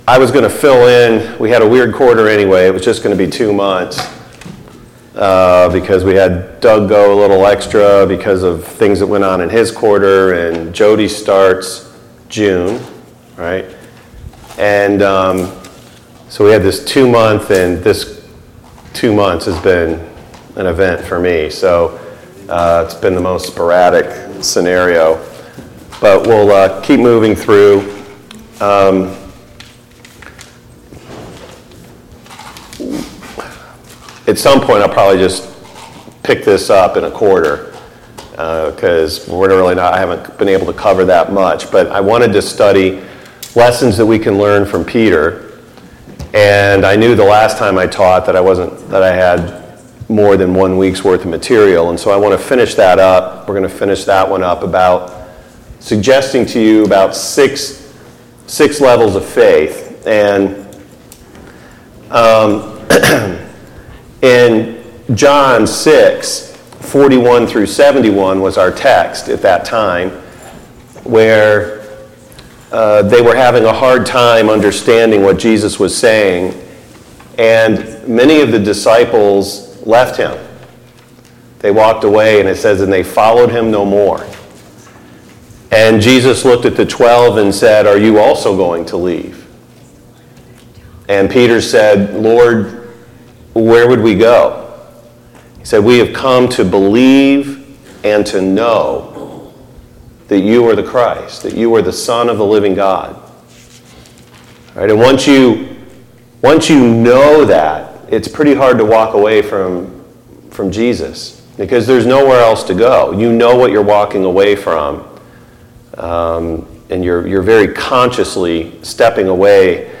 A Study on the Apostle Peter Service Type: Sunday Morning Bible Class Topics